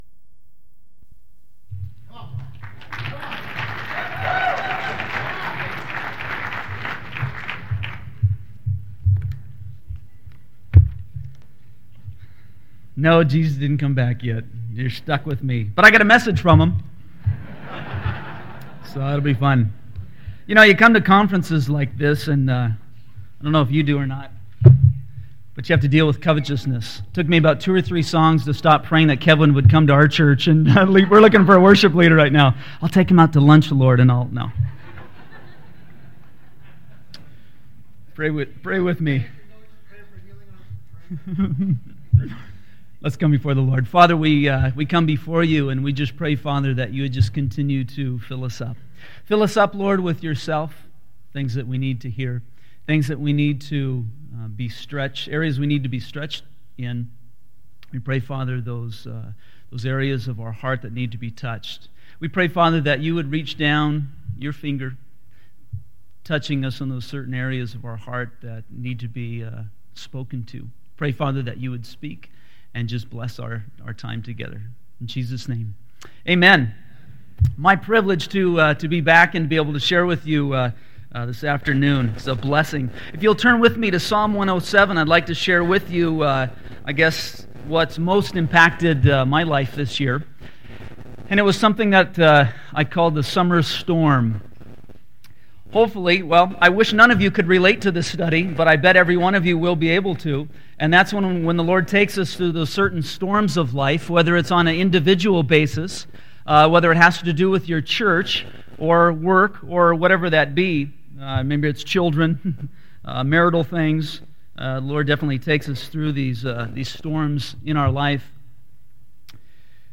2000 Home » Sermons » Session 2 Share Facebook Twitter LinkedIn Email Topics